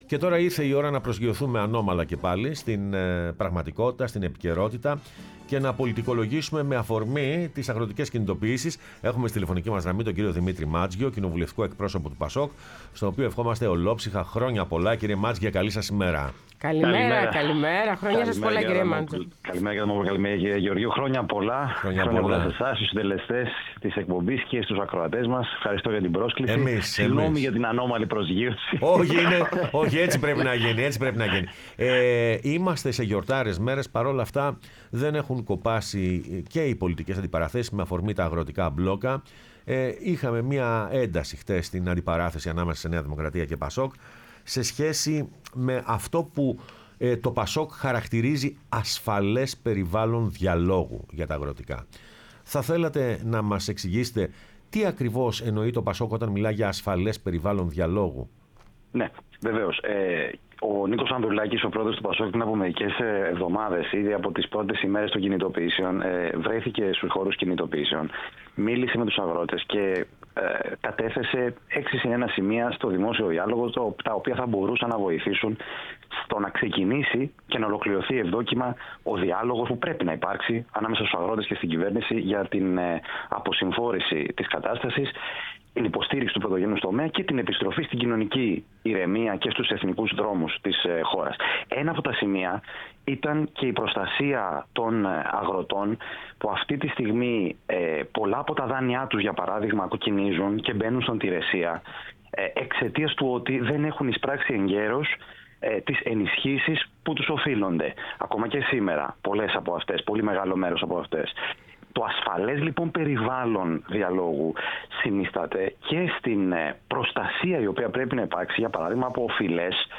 Ο Δημήτρης Μάντζος στο ΕΡΤnews Radio 105,8 | 30.12.2025